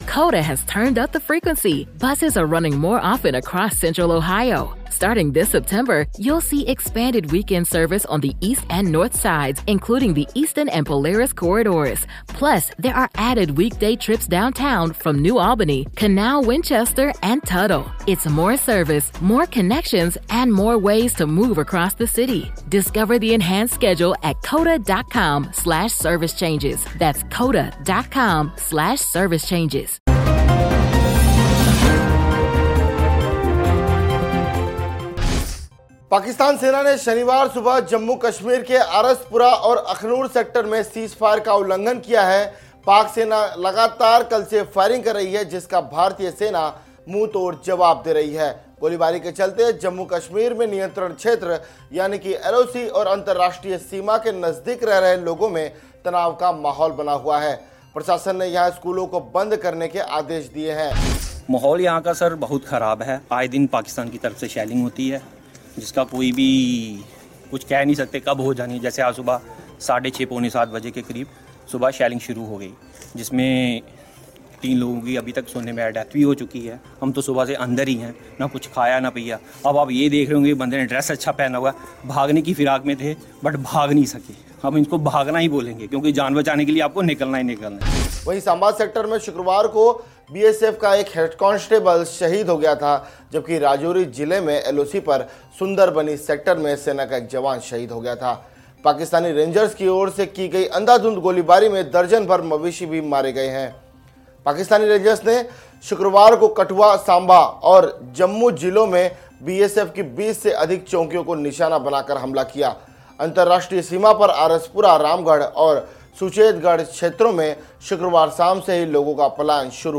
News Report / सरहद पर "ना -पाक" हरकत